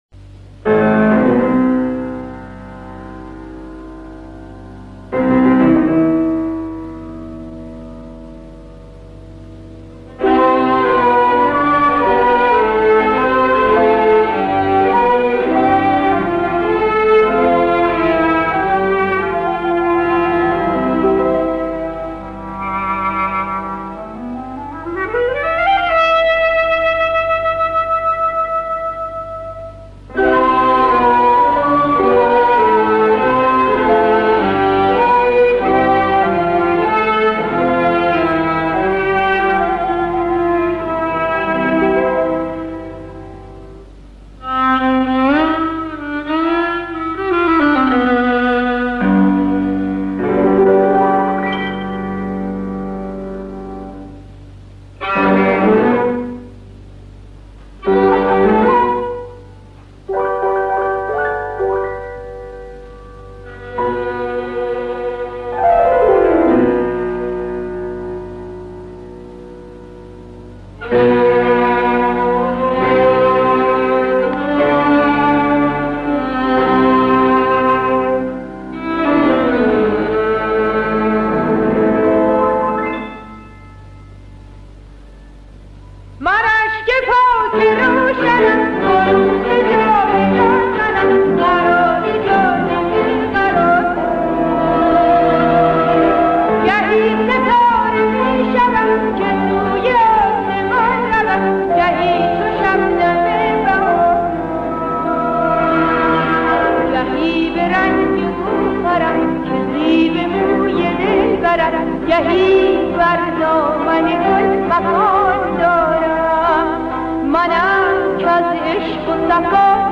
در مایه: دشتی